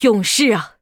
文件 文件历史 文件用途 全域文件用途 Lobato_tk_01.ogg （Ogg Vorbis声音文件，长度0.8秒，129 kbps，文件大小：13 KB） 文件说明 源地址:游戏语音 文件历史 点击某个日期/时间查看对应时刻的文件。 日期/时间 缩略图 大小 用户 备注 当前 2018年11月17日 (六) 03:35 0.8秒 （13 KB） 地下城与勇士  （ 留言 | 贡献 ） 分类:洛巴赫 分类:地下城与勇士 源地址:游戏语音 您不可以覆盖此文件。